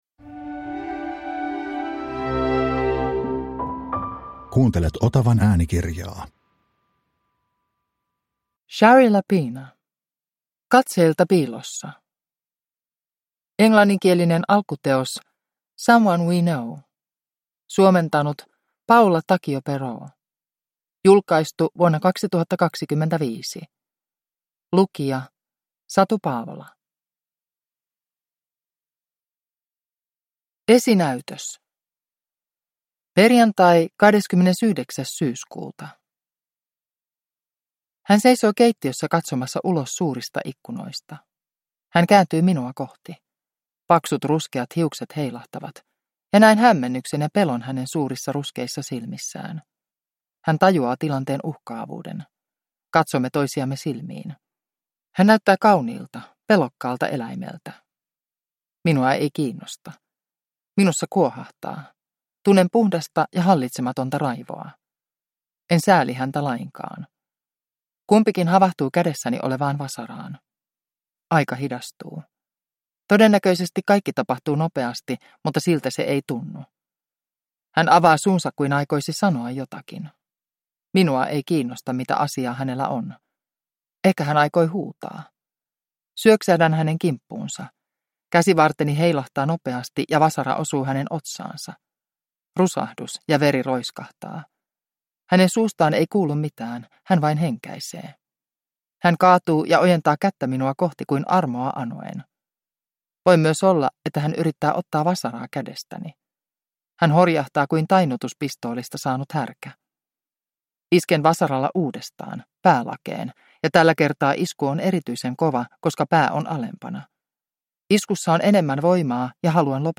Katseilta piilossa (ljudbok) av Shari Lapena